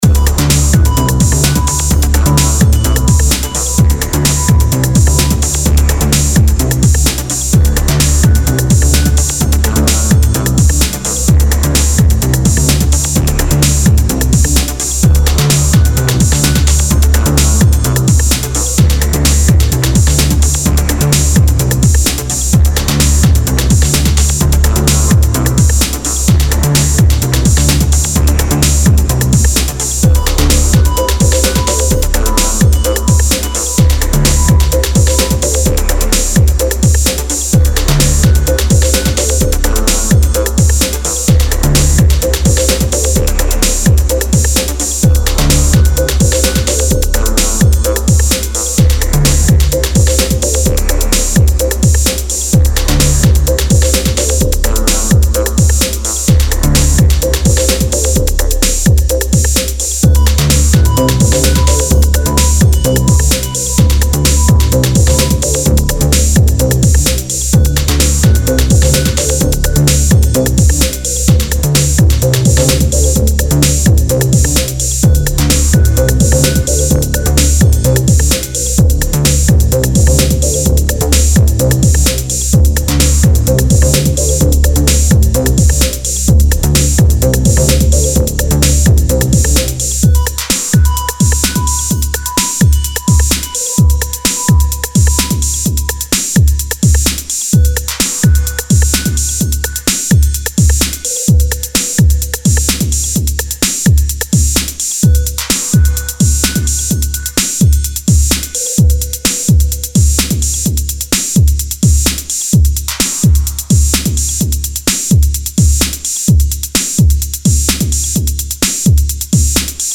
前2作はアシッド・ハウスのフォーカスした印象でしたが、今回はエレクトロに寄せています。